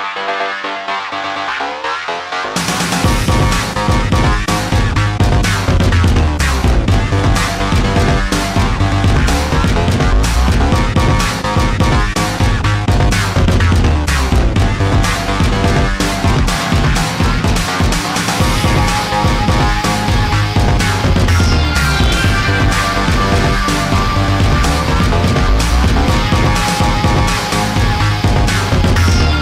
Featured in Electro RIngtones